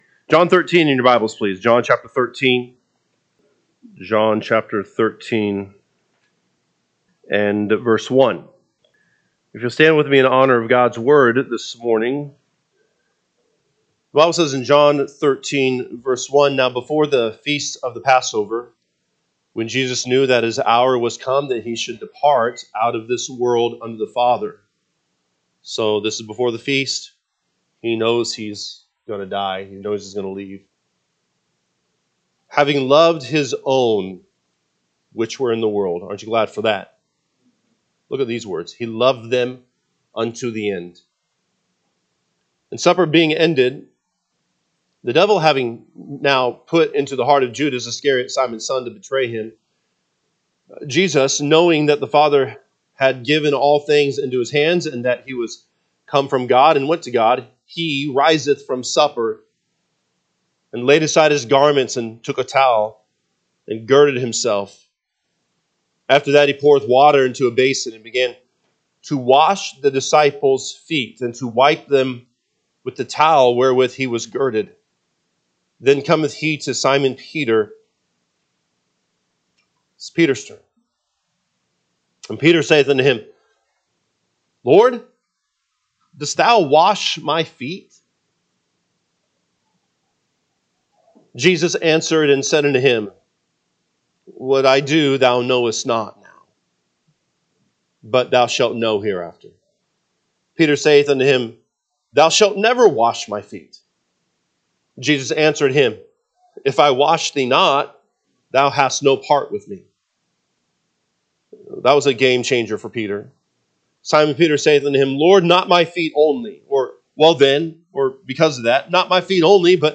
June 22, 2025 am Service John 13:1-17King James Version 13 Now before the feast of the passover, when Jesus knew that his hour was come that he should depart out of this world unto the Father,…
Sunday AM Message